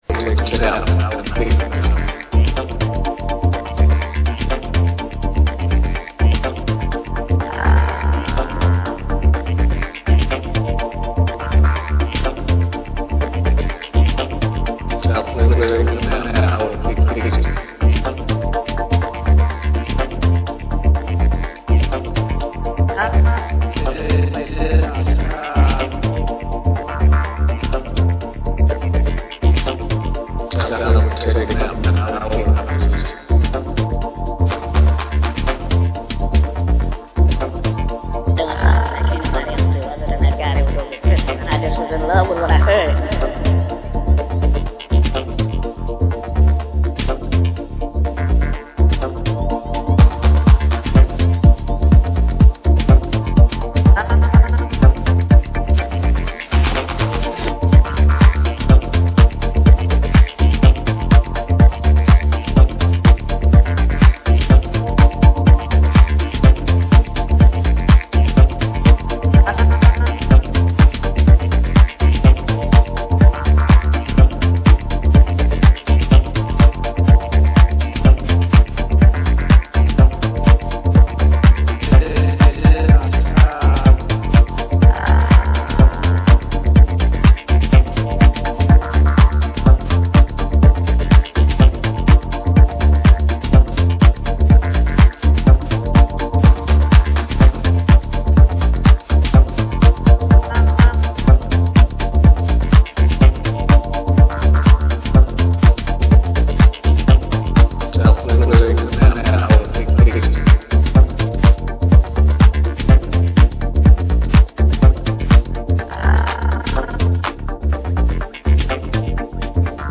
the vanguard of underground house music